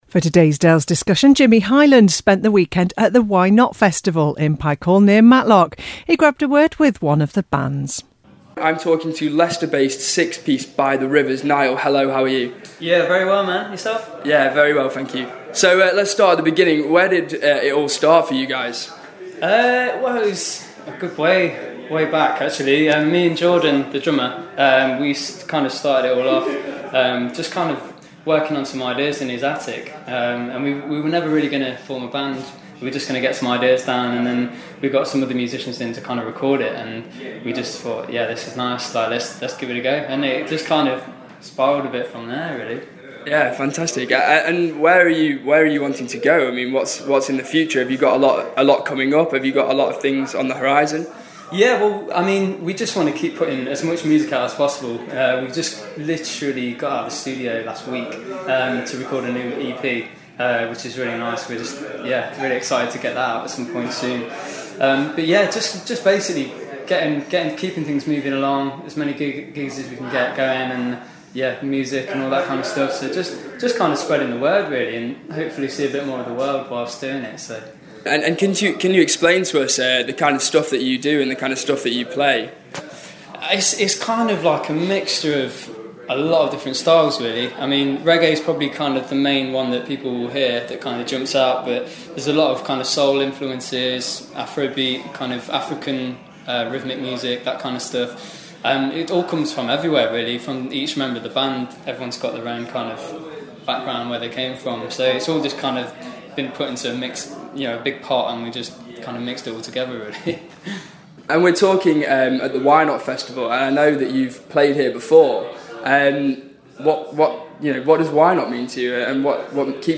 talks to the six piece band By The Rivers at the Y Not Festival in Matlock.